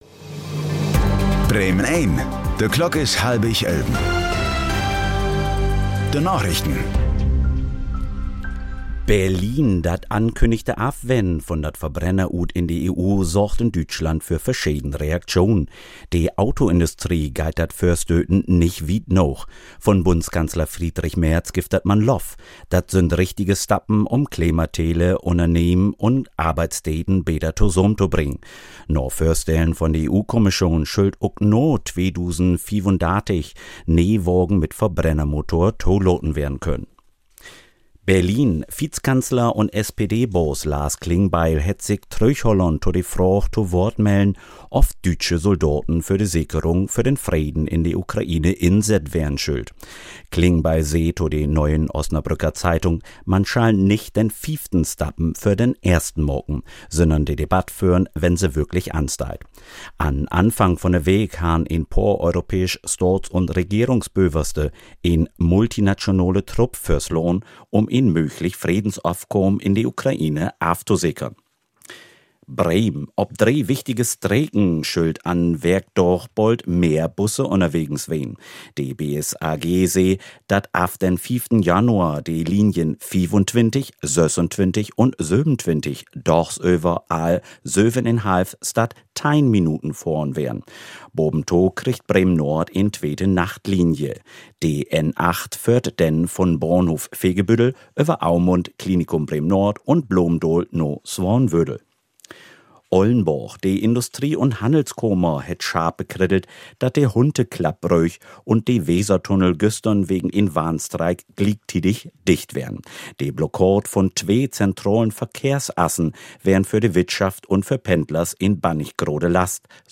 Plattdüütsche Narichten vun'n 17. Dezember 2025